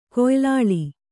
♪ koylāḷi